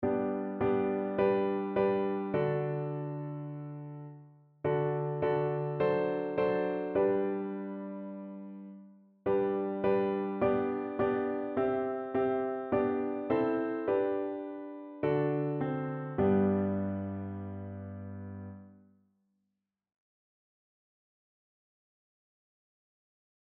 Evangeliumslieder